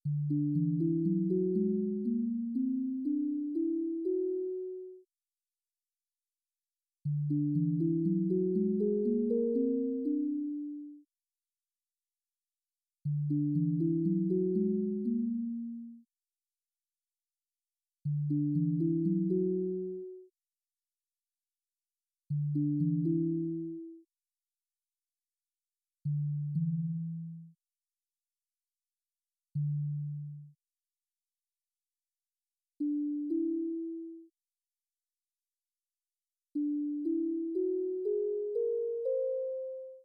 Presentation ( PowerPoint, 8.2 MB), (Paper, 79 KB), (Papers in Review.xls, 107KB), (Sonification of Tools Graph MP3 626 KB